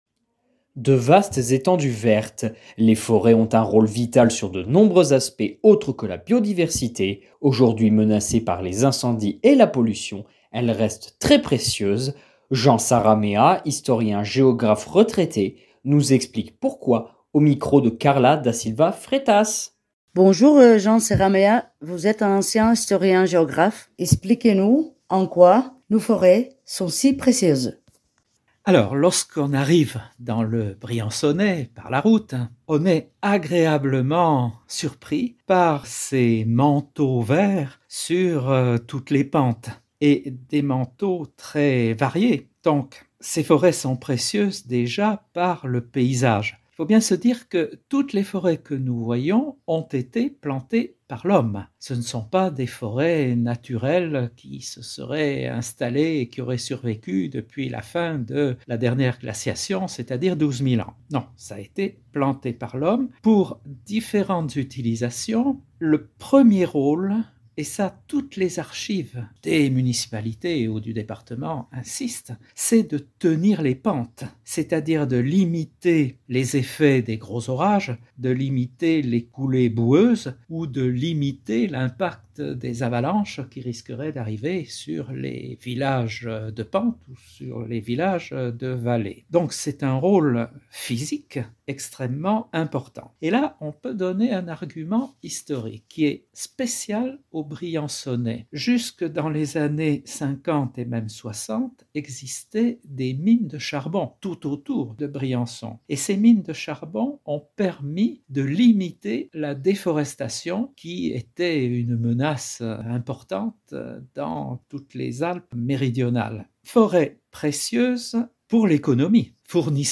historien-géographe retraité nous explique pourquoi au micro